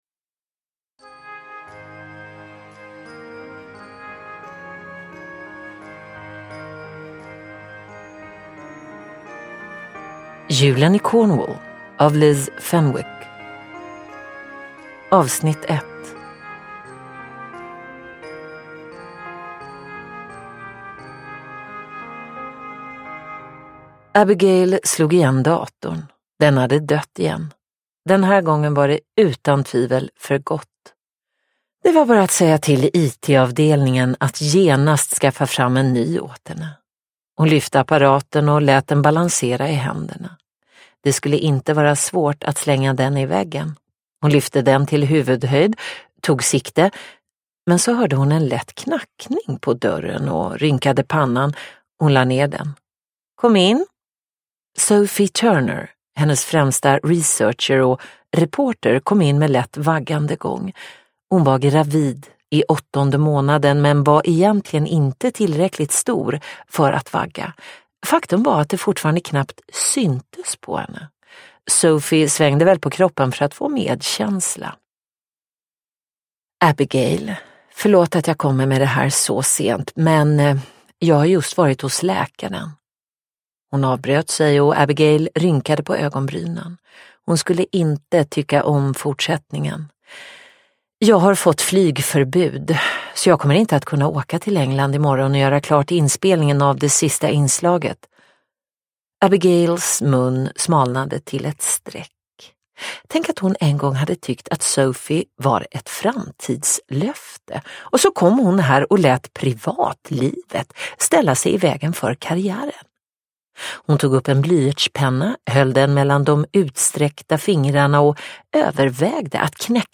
Julen i Cornwall - Del 1 : En julsaga – Ljudbok – Laddas ner